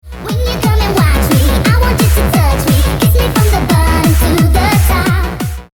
Play, download and share Nightcore original sound button!!!!
nightcore.mp3